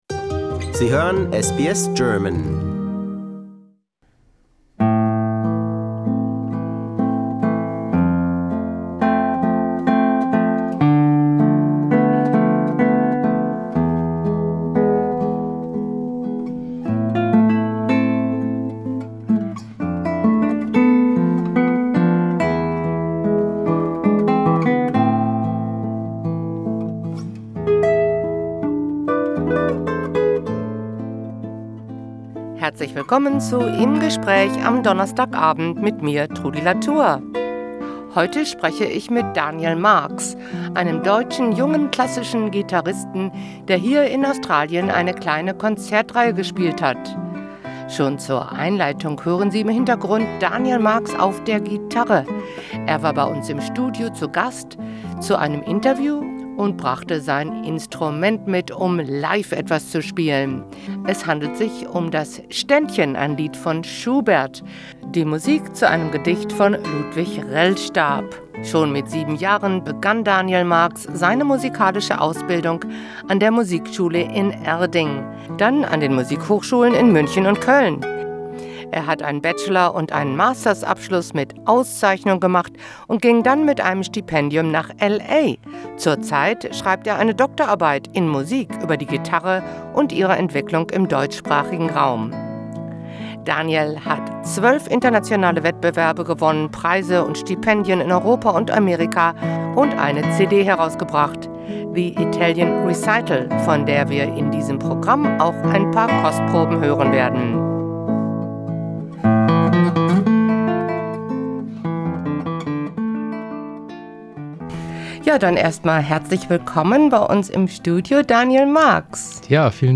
Auch für uns spielt er live in Studio ein Ständchen. Wir sprechen über das Repertoire für die Gitarre, ihren Stellenwert in der klassischen Musik und ihre Entwicklung über die vielen Jahrzehnte, seitdem es dieses Saiteninstrument und ihre Vorgänger - wie die Laute - schon gibt.